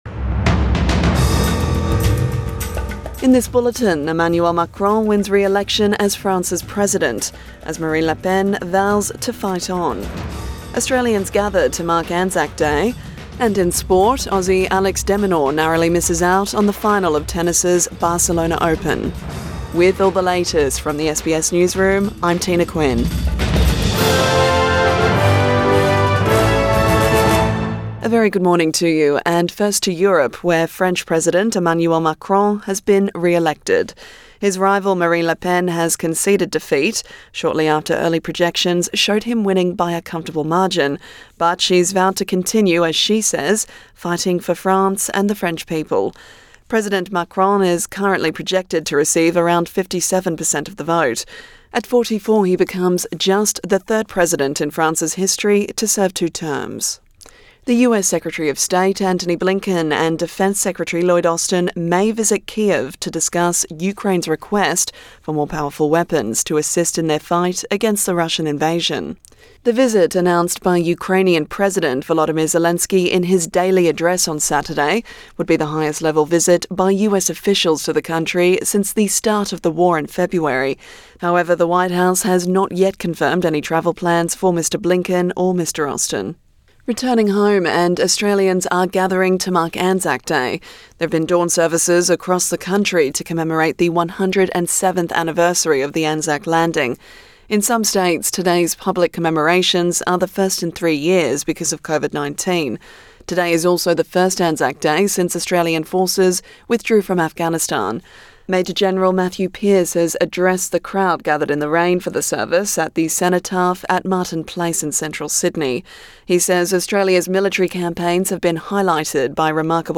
AM bulletin 25 April 2022